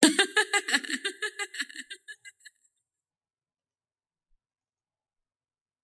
Metro Laughter 2.wav